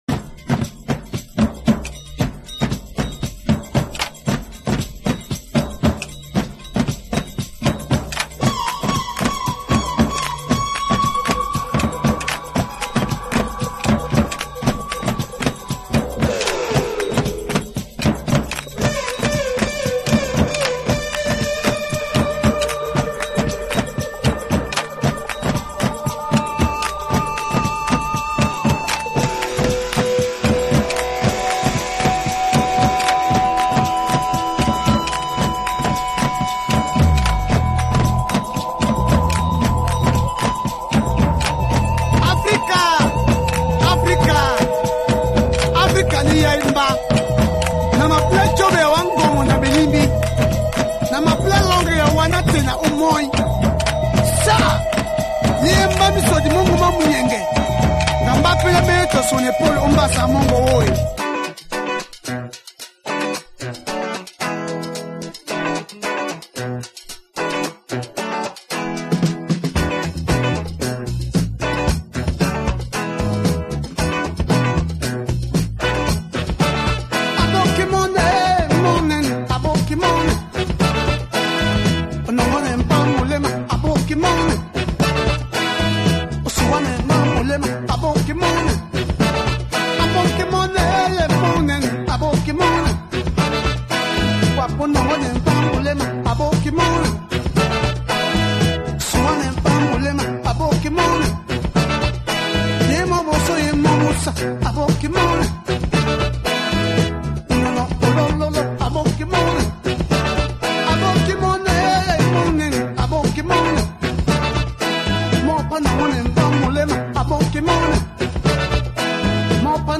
Afro funk LP
High level of grooviness
funky guitarist
Great afro funk !